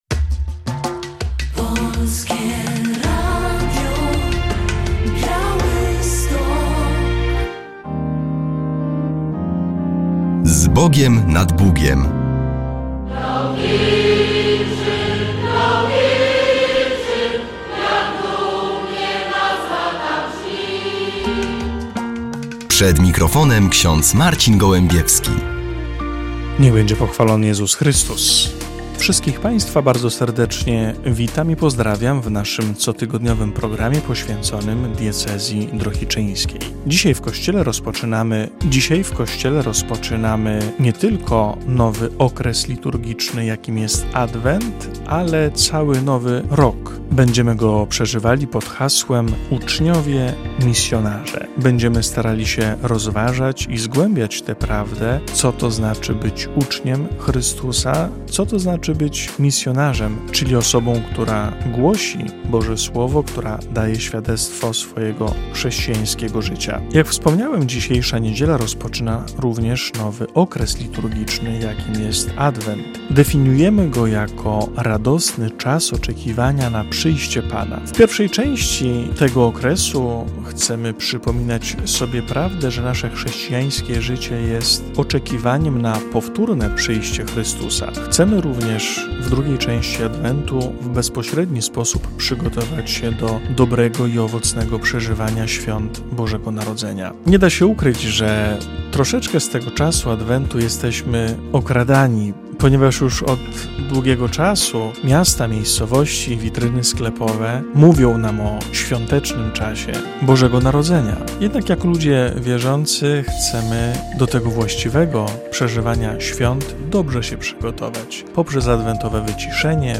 W audycji relacja ze święta patronalnego Akcji Katolickiej, Katolickiego Stowarzyszenia Młodzieży oraz Wyższego Seminarium Duchownego w Drohiczynie.